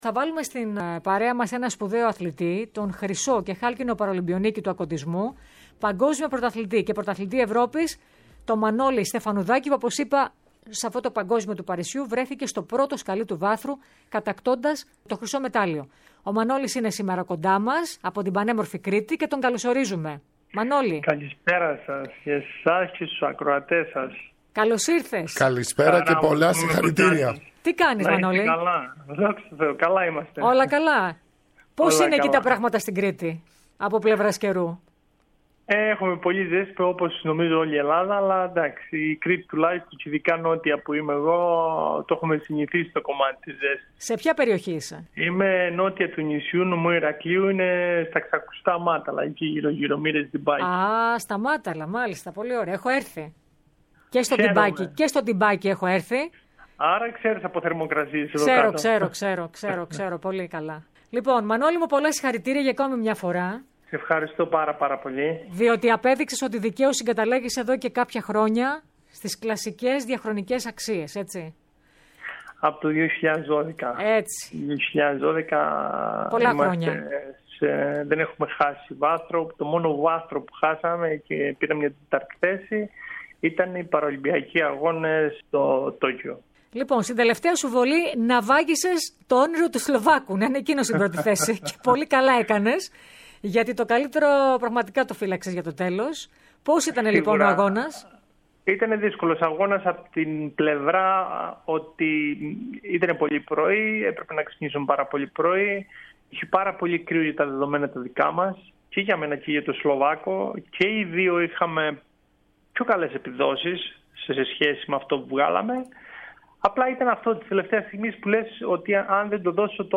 Ο Παραολυμπιονίκης και Παγκόσμιος Πρωταθλητής στίβου ΑμεΑ μίλησε στην ΕΡΑ ΣΠΟΡ για την εμπειρία του και το χρυσό μετάλλιο στο Παγκόσμιο Πρωτάθλημα του Παρισίου αλλά και για το ατύχημά του, στο ενδεχόμενο να το άλλαζε αν μπορούσε.